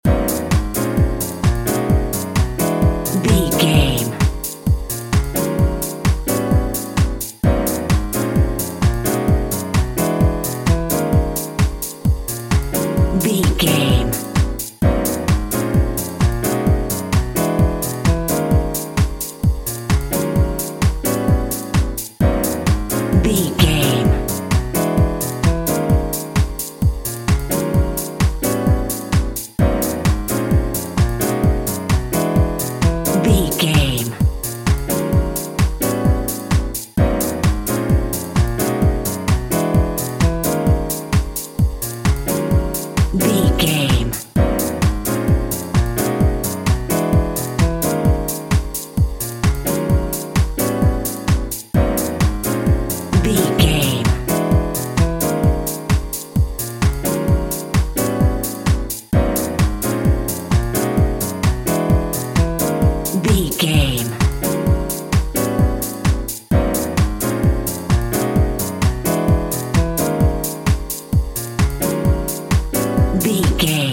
Aeolian/Minor
E♭
Fast
groovy
smooth
futuristic
drum machine
piano
house
techno
synth lead
synth bass
synth drums